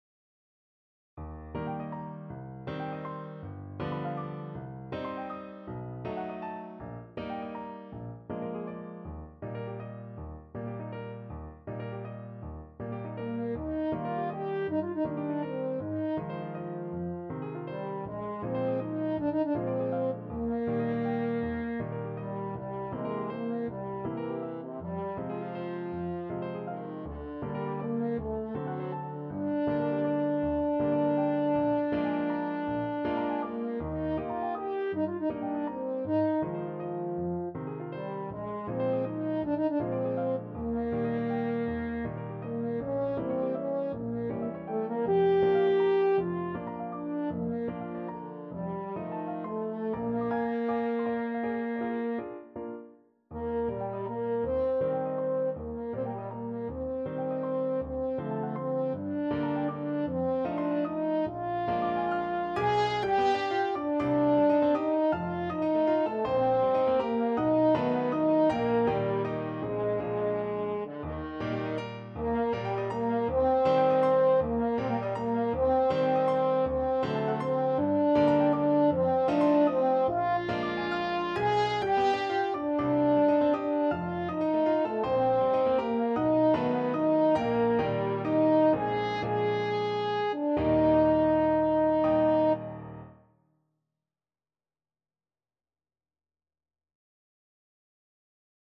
6/8 (View more 6/8 Music)
Classical (View more Classical French Horn Music)